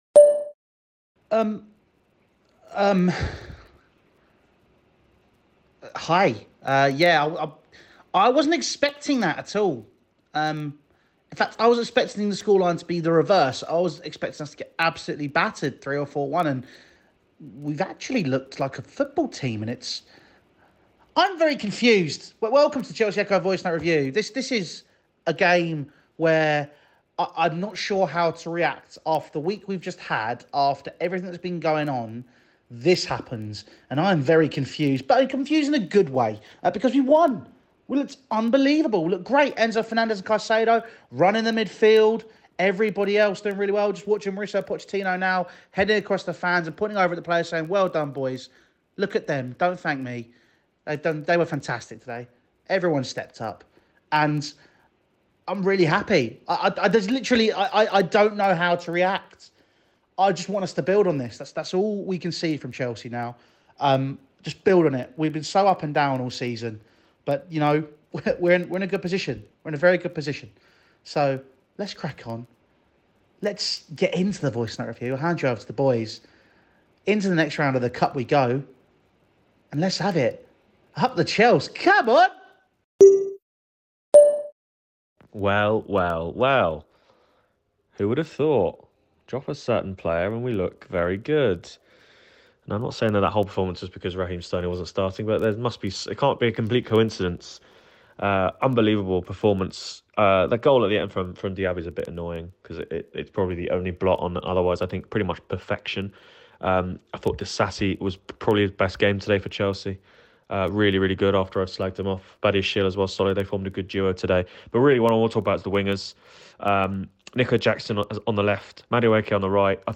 Well that was unexpected... | Aston Villa 1-3 Chelsea Voicenote Review